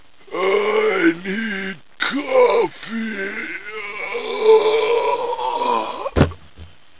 My famous 4 a.m. quote: " I need coffee" (fainting).